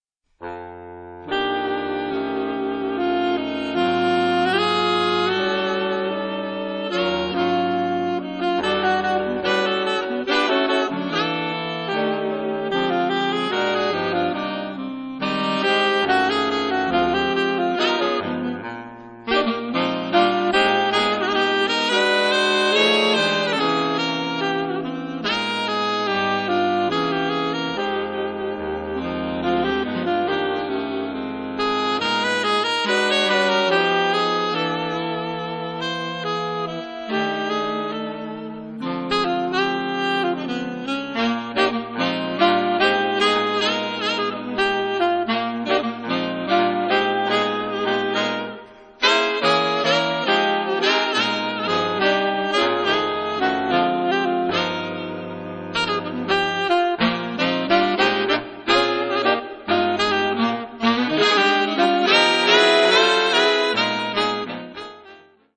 Big band jazz
his LA based big band of top studio pros